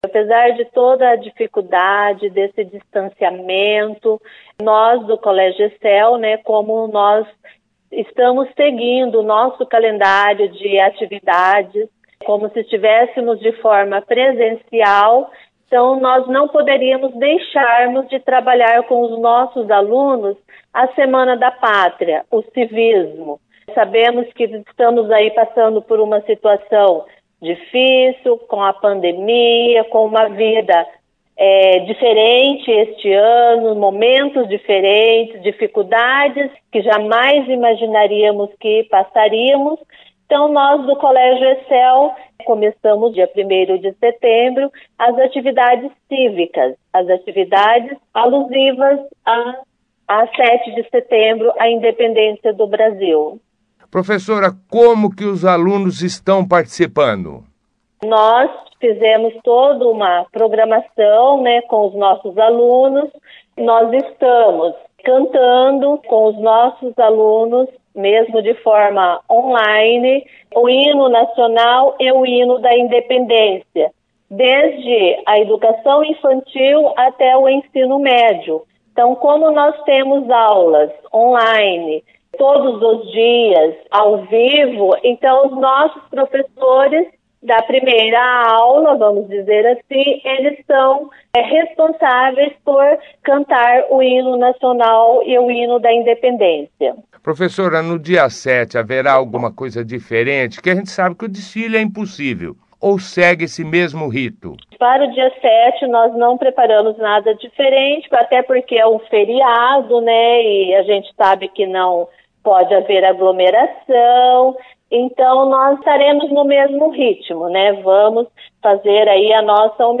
participou da 1ª edição do jornal Operação Cidade desta quinta-feira, 03/09, falando como está sendo desenvolvida estas atividades e também da participação dos alunos nas aulas on-line desenvolvida pelo colégio.